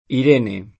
[ ir $ ne ; sp. ir % ne ]